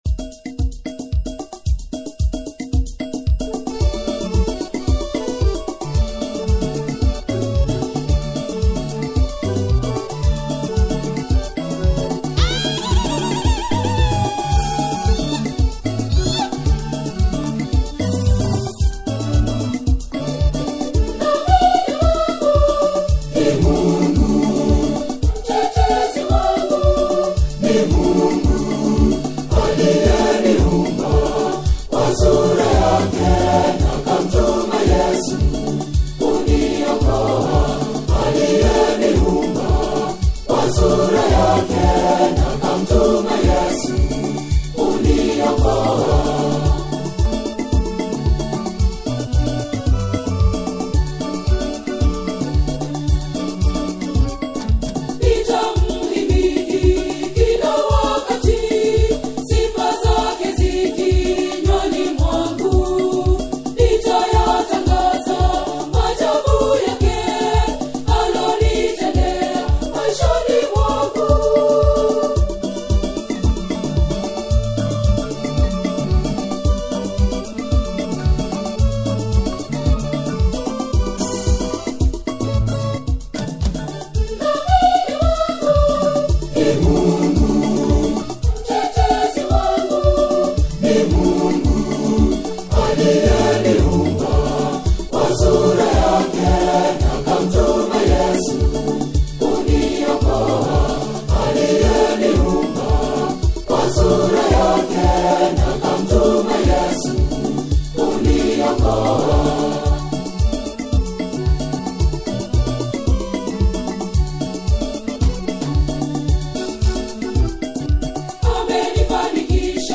a soul-stirring and prayerful single